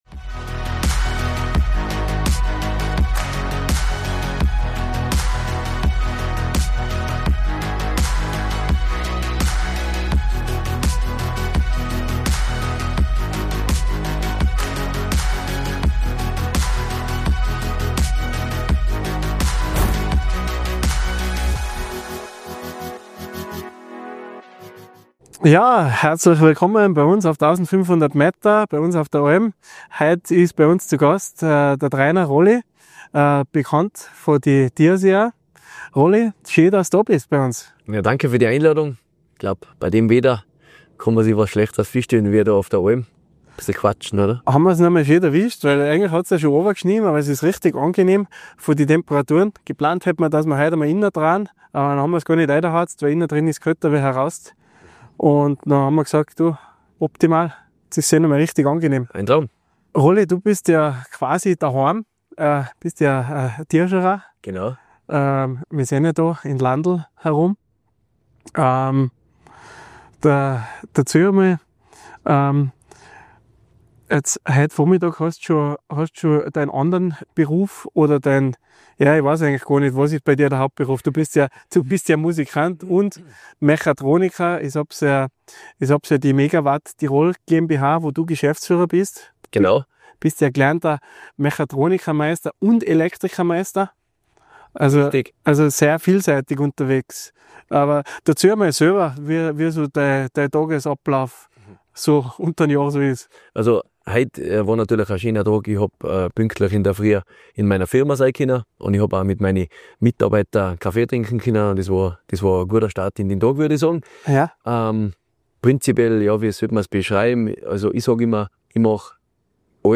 Ein ehrliches Gespräch über Leidenschaft, Disziplin, Musik, Heimat und Erfolg – aufgenommen hoch oben in den Tiroler Bergen.